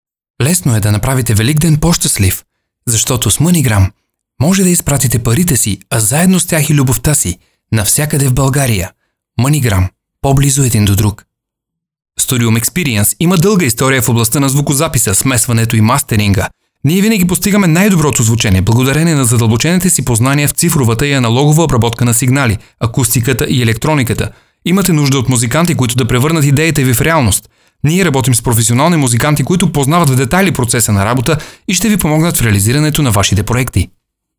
BUL NN EL 01 eLearning/Training Male Bulgarian